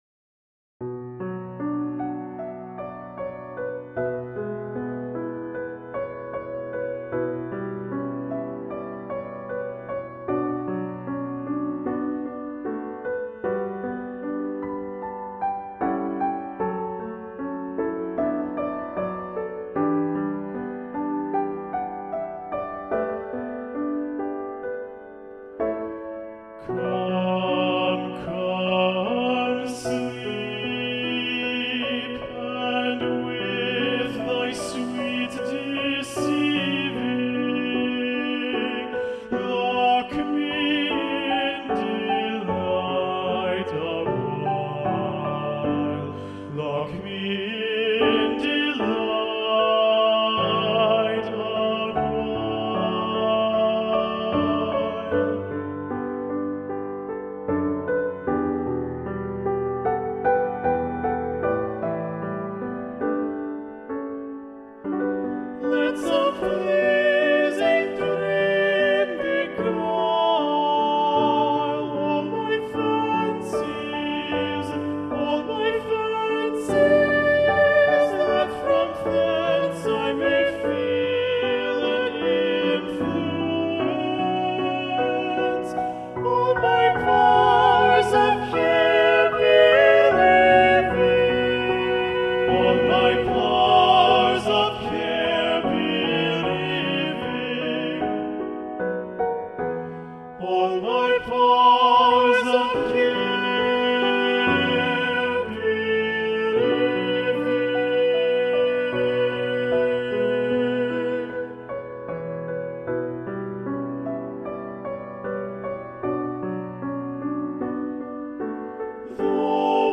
Choral Music
GENERAL MUSIC — WITH PIANO